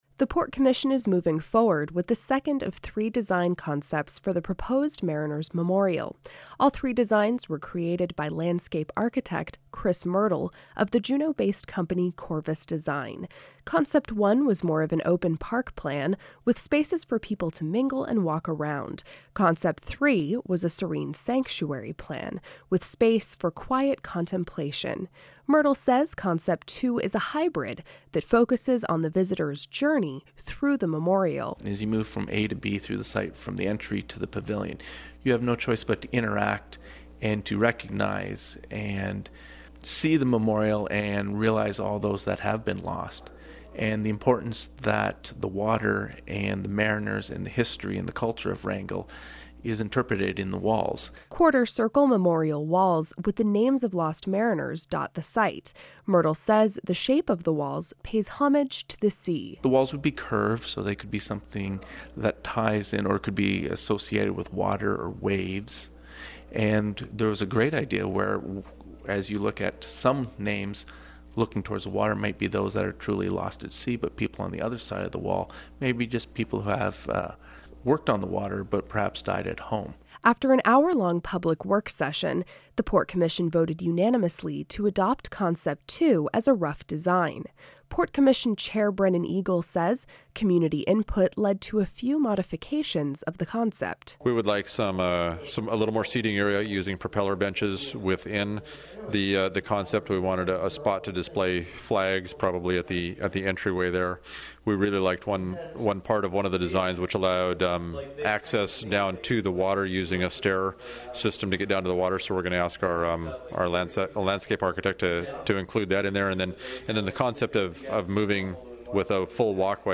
Did you appreciate this report?